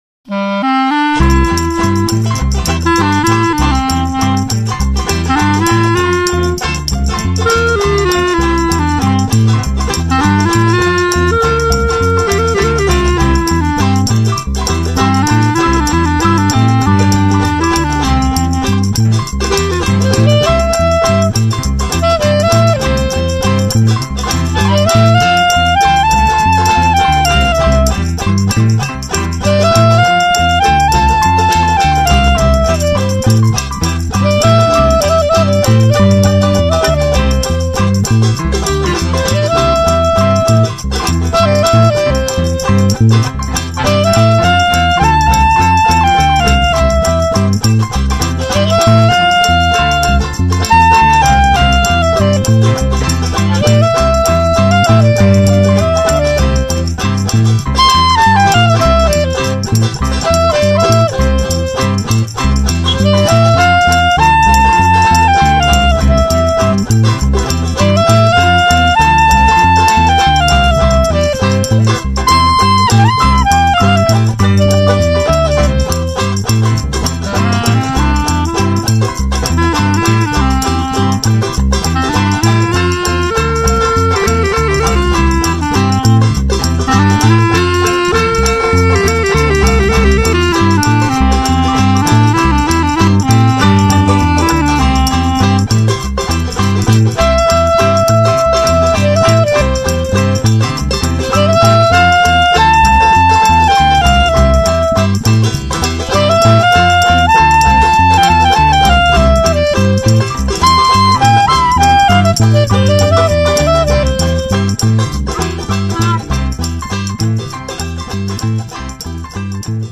Punk Dixieland Style (920K) recorded on June 8 & 9, 2008
Piano, Bass, Banjo, Tambourine and clarinet